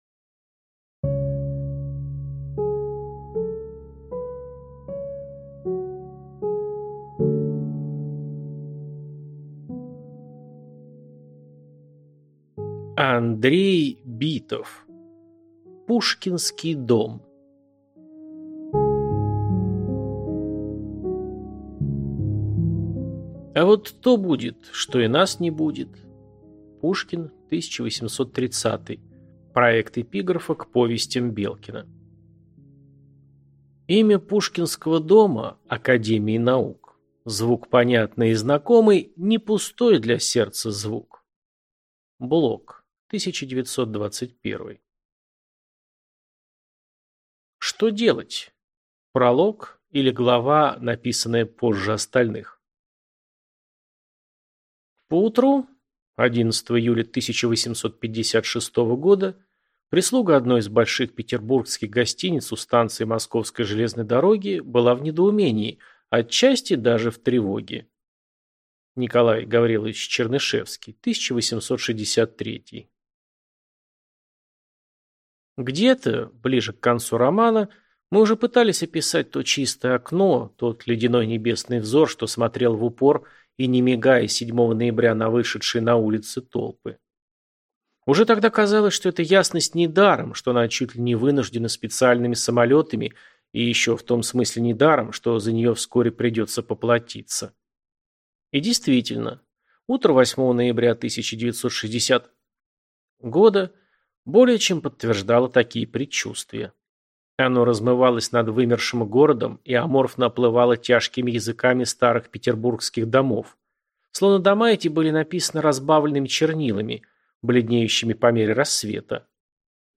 Аудиокнига Пушкинский дом | Библиотека аудиокниг
Прослушать и бесплатно скачать фрагмент аудиокниги